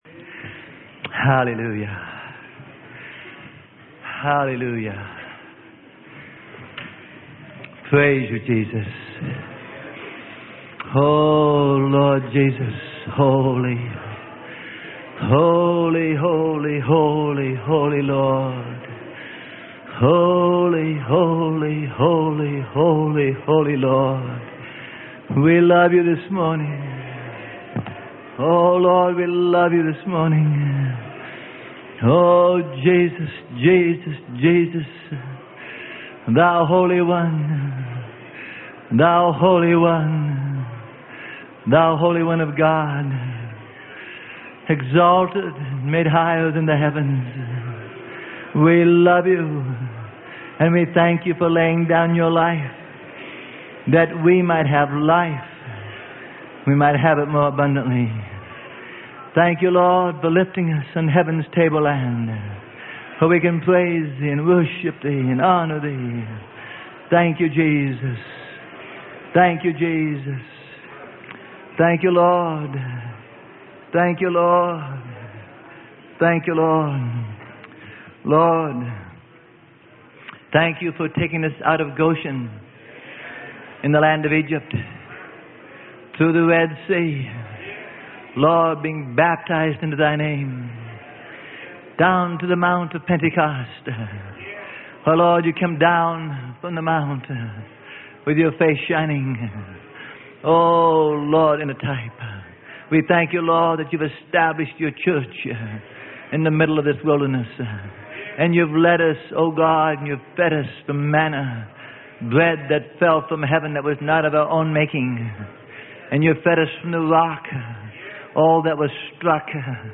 Sermon: CHRISTIANITY IN BALANCE.